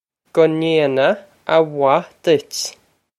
Pronunciation for how to say
Guh nain-ah ah wah ditch
This is an approximate phonetic pronunciation of the phrase.